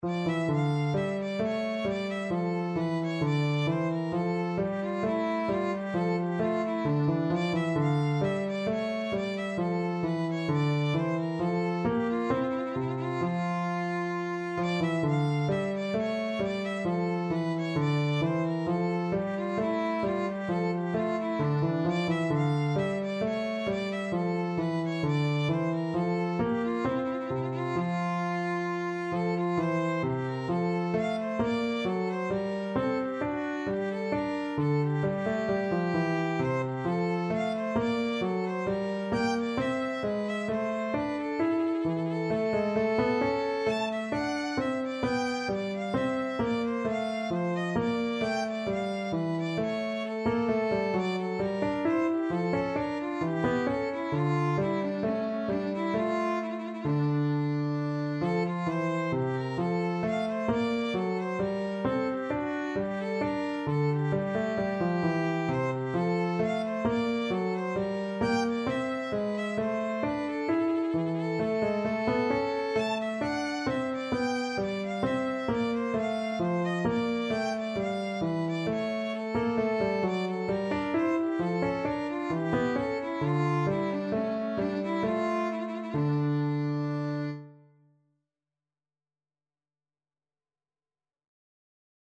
Free Sheet music for Violin
Play (or use space bar on your keyboard) Pause Music Playalong - Piano Accompaniment Playalong Band Accompaniment not yet available transpose reset tempo print settings full screen
Allegro = c. 132 (View more music marked Allegro)
4/4 (View more 4/4 Music)
D minor (Sounding Pitch) (View more D minor Music for Violin )
Classical (View more Classical Violin Music)